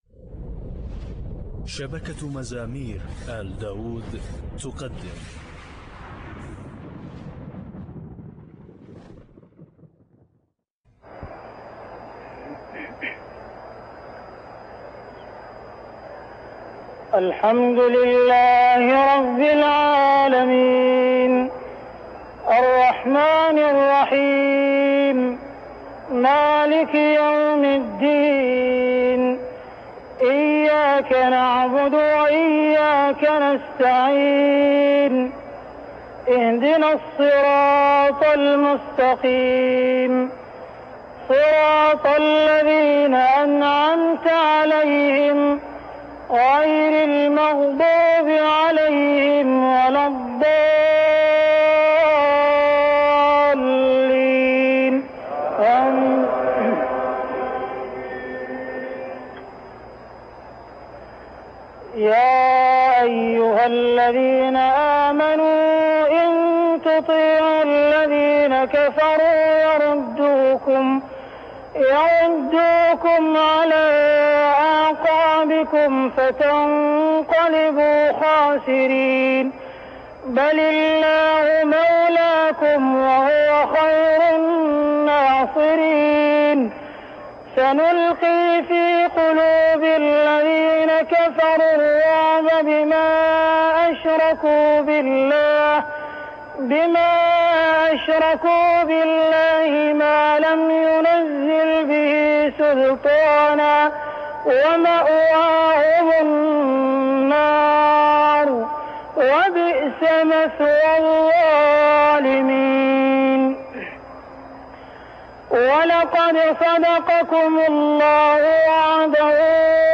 تراويح الحرم المكي 1404هـ
تلاوة نادرة لما تيسر من سورة آل عمران مع الشفع والوتر والقنوت- للشيخ السديس